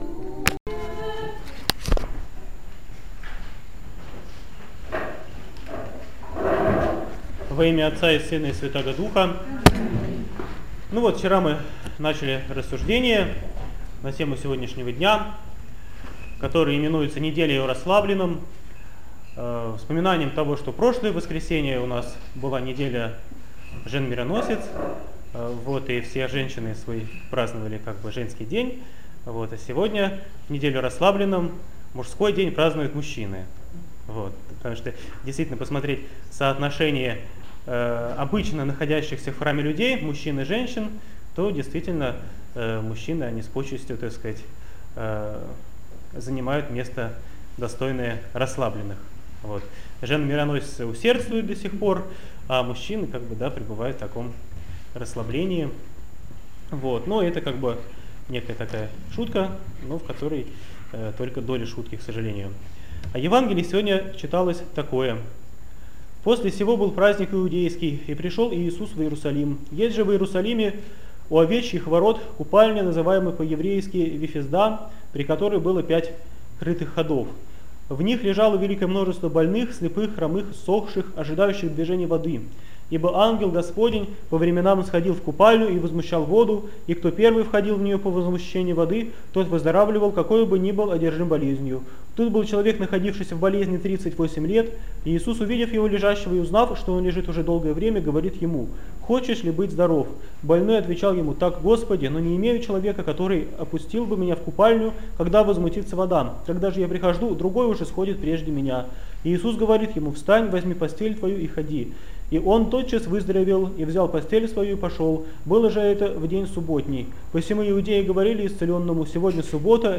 БОГОЛЮБСКИЙ ХРАМ ПОСЕЛОК ДУБРОВСКИЙ - Проповедь в Неделю 4-ю по Пасхе, о расслабленном.